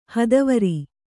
♪ hadavari